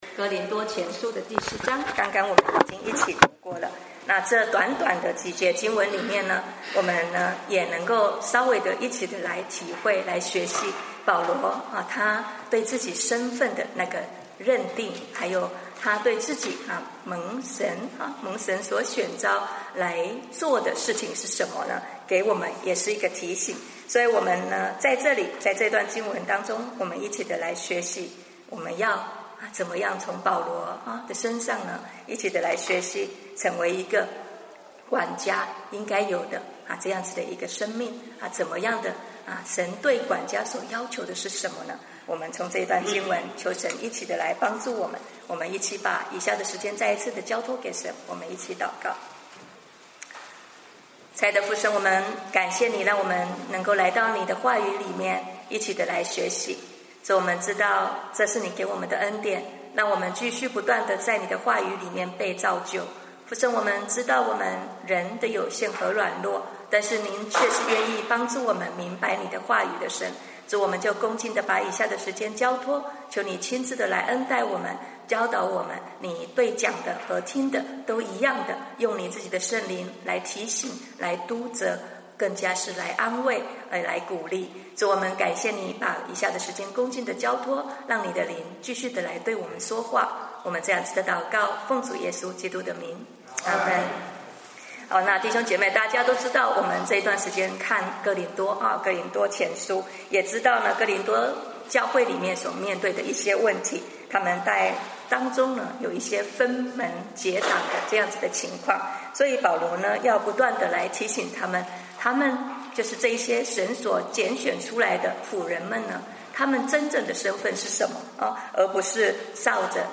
主日讲道音频